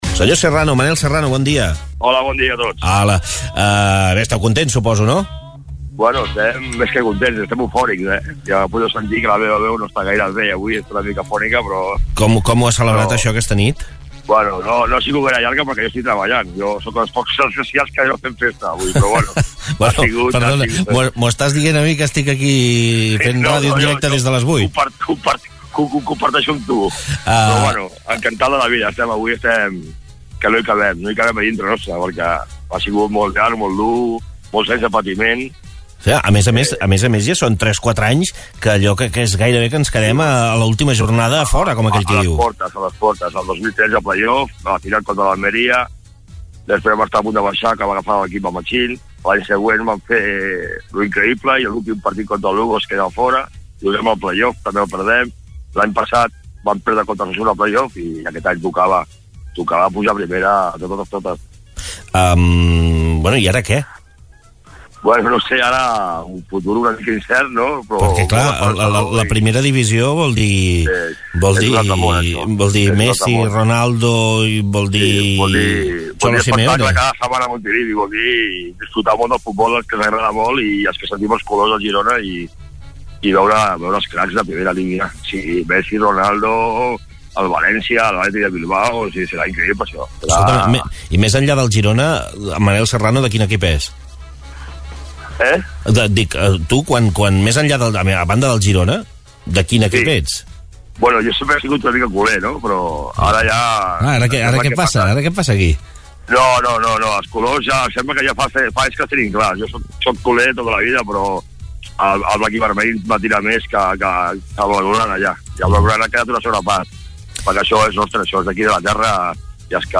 Entrevistes SupermatíEsportsNotíciesSant Feliu de GuíxolsSupermatí